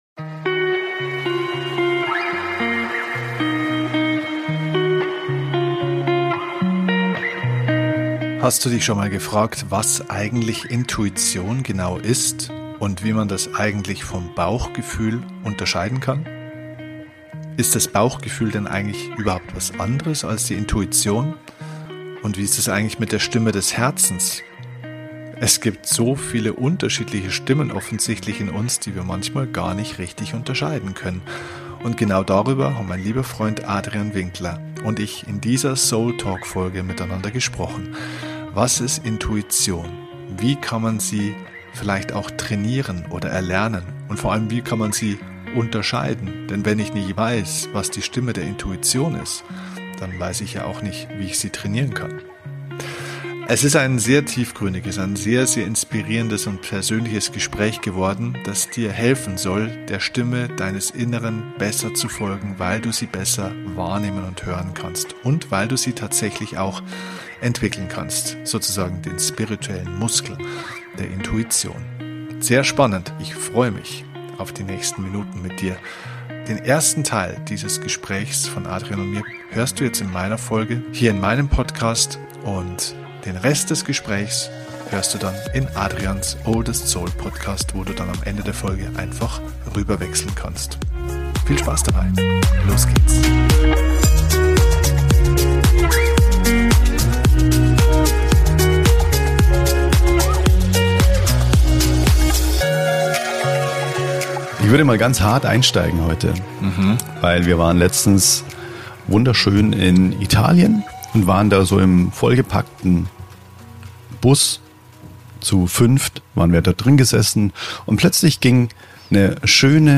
Wie immer bei diesem Format: kein Skript, nur zwei Freunde die miteinander reden.